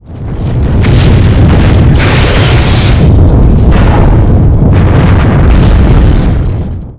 Detonationswelle
detonationswelle